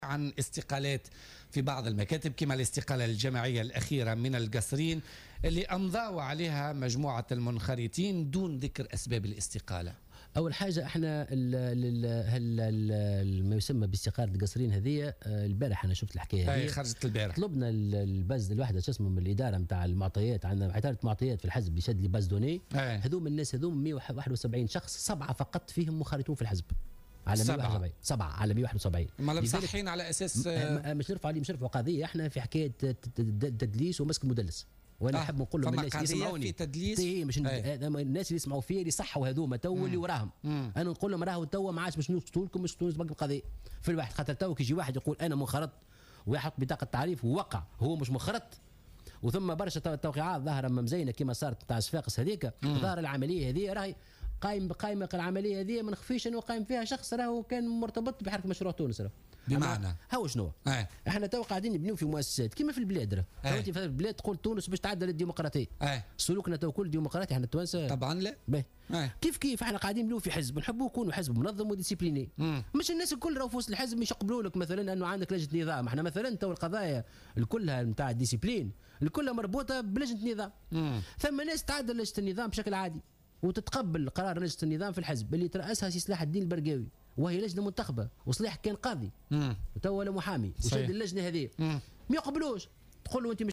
قال الأمين العام لحركة مشروع تونس، محسن مرزوق ضيف بولتيكا اليوم الإثنين 3 جويلية 2017 إن حزبه سيقاضي المدلسين الذي ادعوا انخراطهم في حركة مشروع تونس وأعلنوا أمس عن استقالتهم في القصرين.